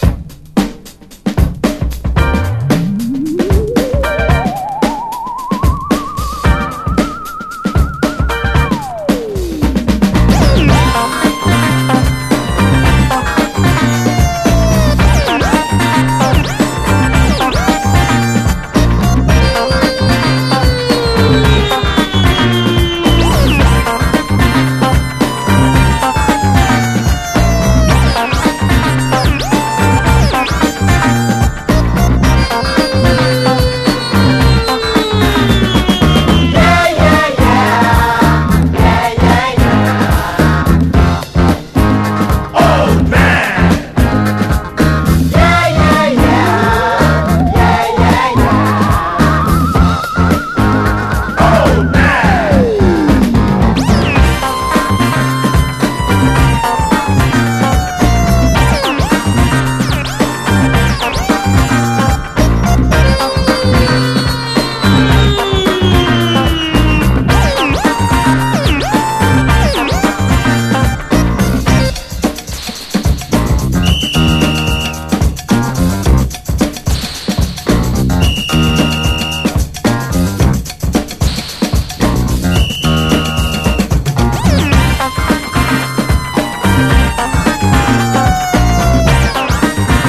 RHYTHM & BLUES / BLUES
ブルース・ハープを吹きながら力強く歌い上げる大御所ブルース・マン、デビュー・アルバム！